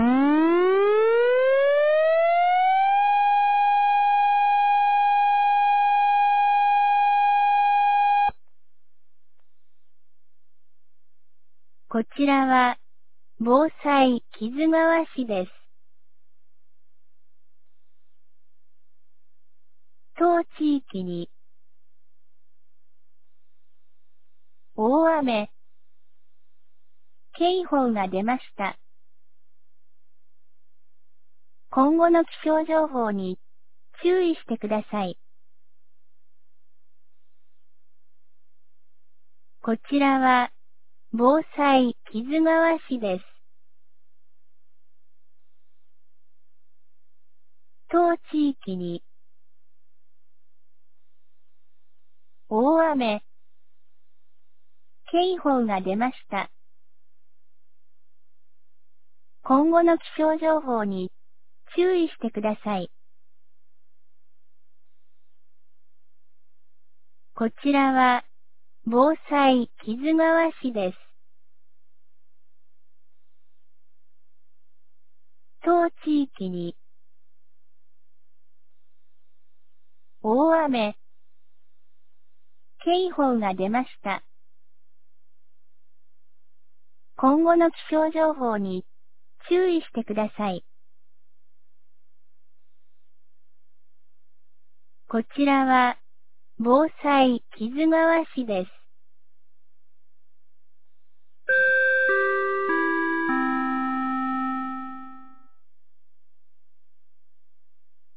2025年06月24日 14時52分に、木津川市より市全域へ放送がありました。
放送音声